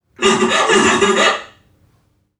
NPC_Creatures_Vocalisations_Robothead [60].wav